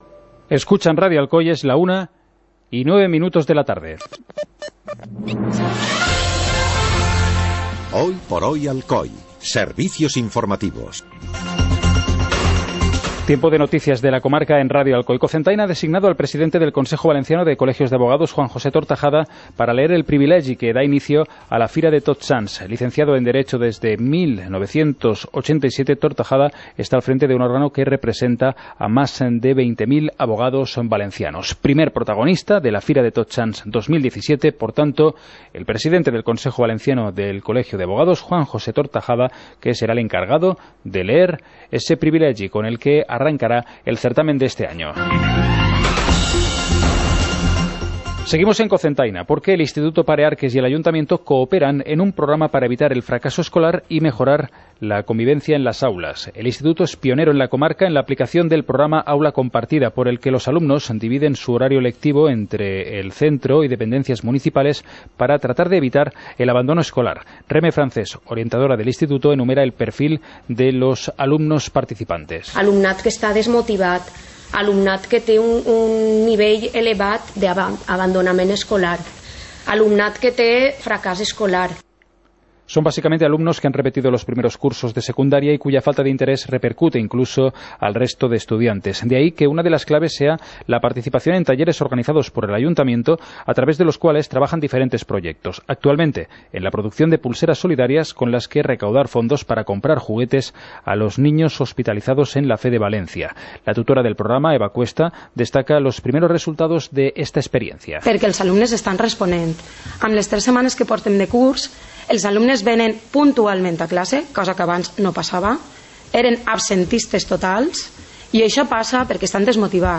Informativo comarcal - miércoles, 27 de septiembre de 2017